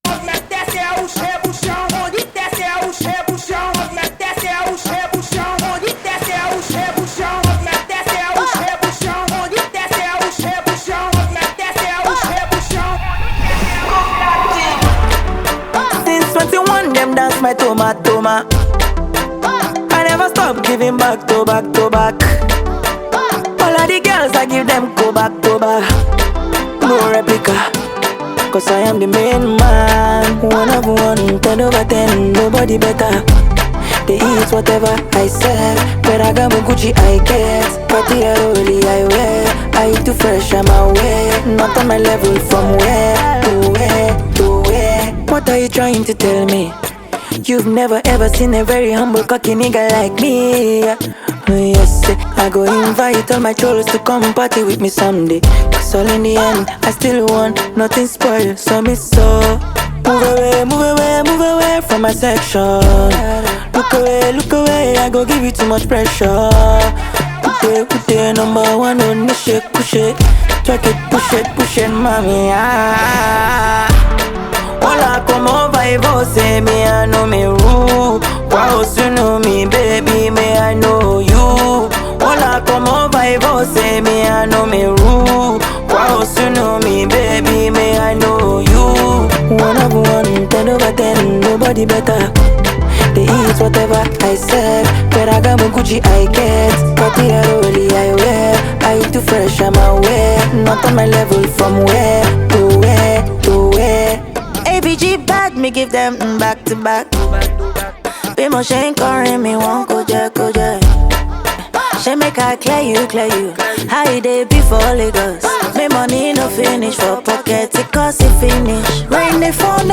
much appreciated AfroBeat song known as
high vibe song